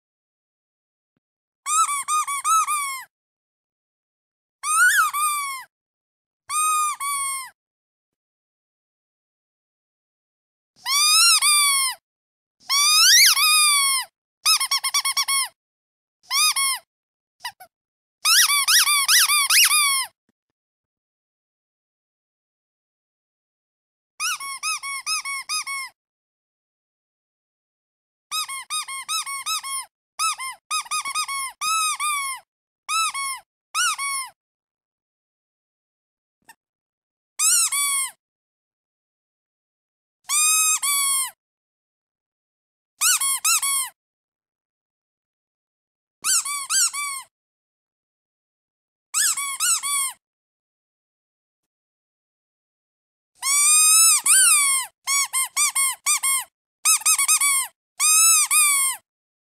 جلوه های صوتی
دانلود صدای اردک 2 از ساعد نیوز با لینک مستقیم و کیفیت بالا